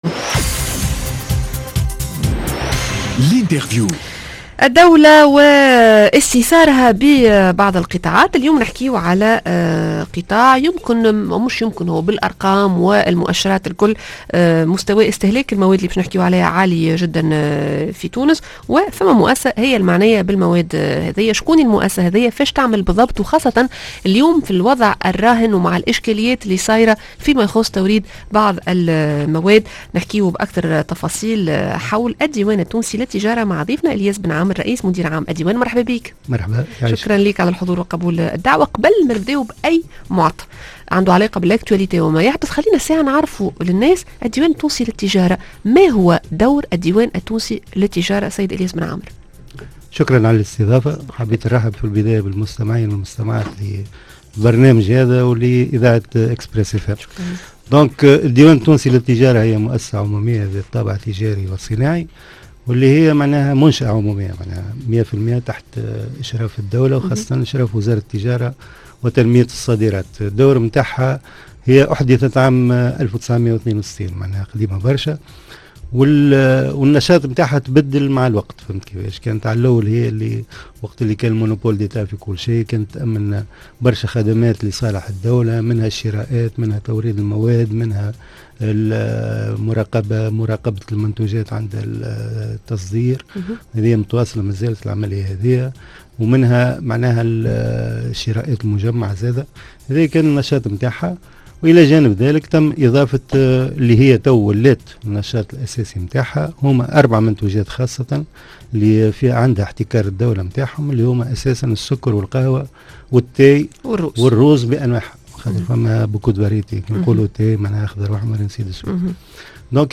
L'interview: الدولة مازالت تستأثر قطاع السكر القهوة الشاي و الارز. الياس بن عامر رئيس مدير عام الديوان التونسي للتجارة